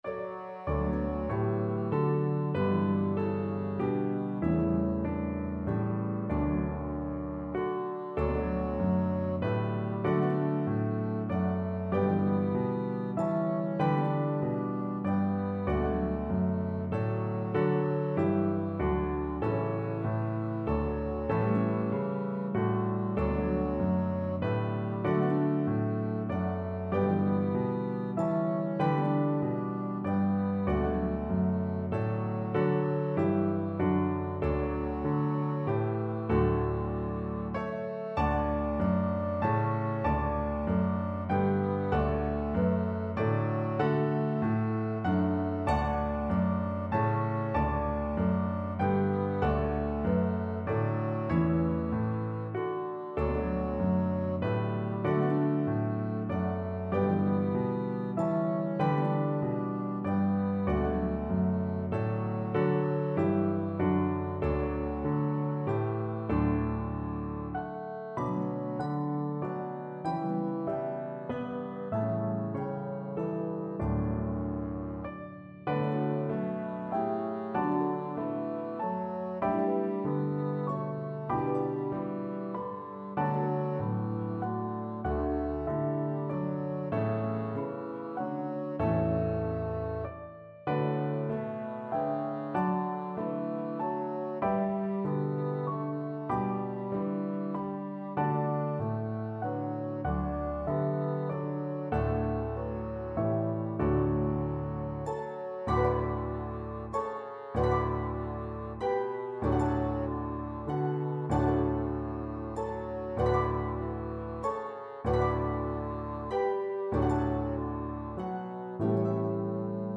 Harp, Piano, and Bassoon version